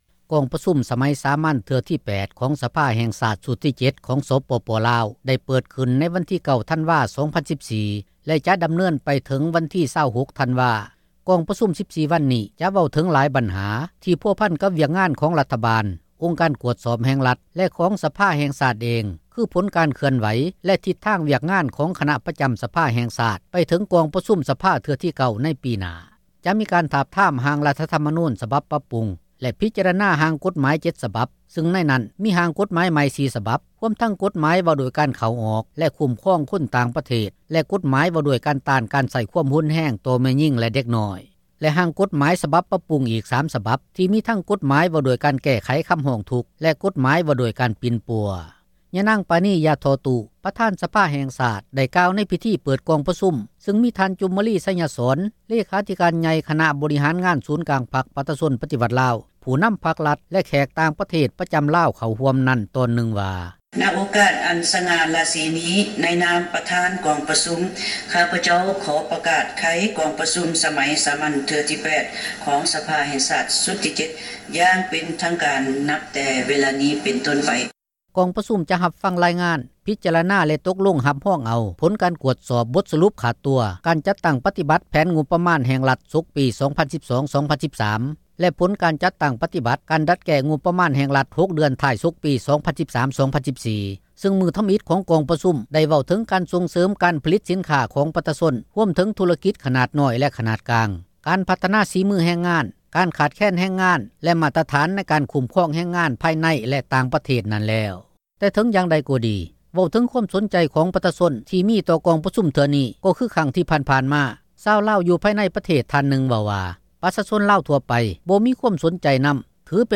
ກອງປະຊຸມສມັຍສາມັນຄັ້ງທີ 8 – ຂ່າວລາວ ວິທຍຸເອເຊັຽເສຣີ ພາສາລາວ
ຍານາງ ປານີ ຢາທໍ່ຕູ້ ປະທານ ສະພາ ແຫ່ງຊາດ ກ່າວ ໃນພິທີ ເປີດ ກອງປະຊຸມ ຊຶ່ງ ມີທ່ານ ຈູມມະລີ ໄຊຍະສອນ ເລຂາທິການ ໃຫຍ່ ຄນະ ບໍຣິຫານ ງານ ສູນກາງ ພັກ ປະຊາຊົນ ປະຕິວັດ ລາວ, ຜູ້ນໍາ ພັກ-ຣັດ ແລະ ແຂກຕ່າງ ປະເທສ ປະຈໍາ ລາວ ເຂົ້າຮ່ວມ ນັ້ນວ່າ: